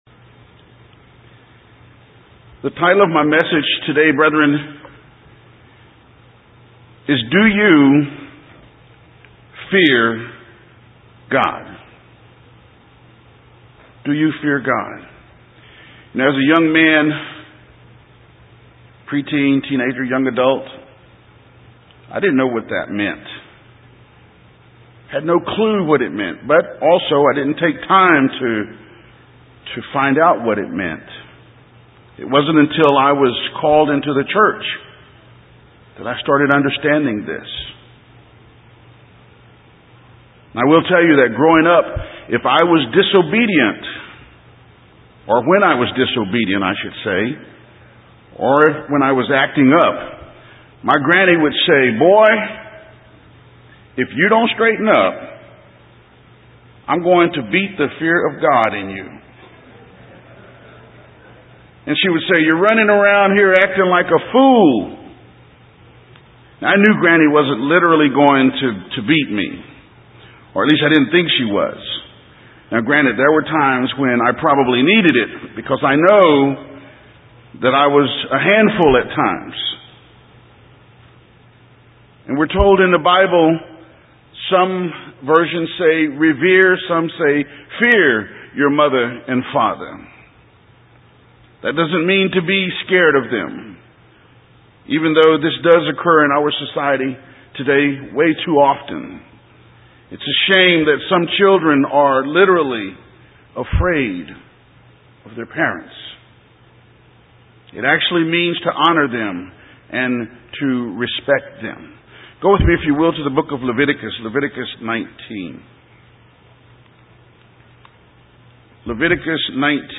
Given in San Antonio, TX